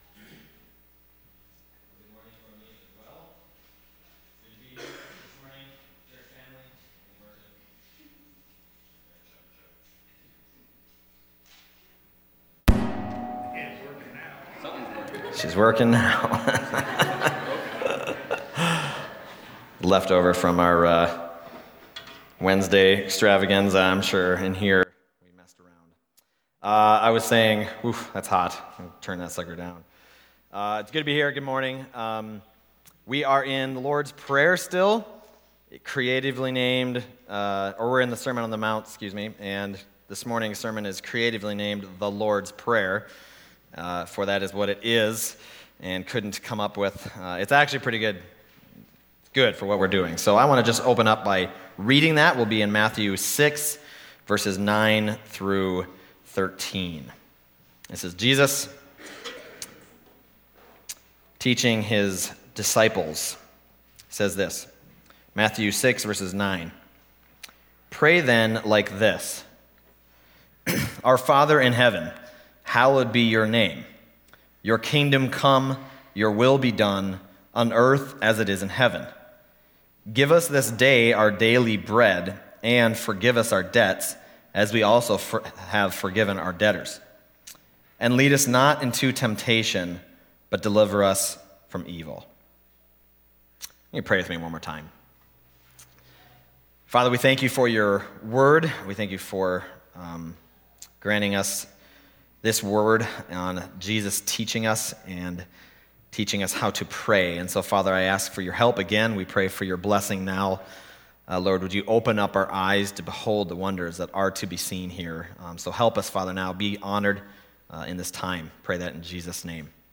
Matthew 6:9-13 Service Type: Sunday Morning Matthew 6:9-13 « Whose Reward Are You Seeking?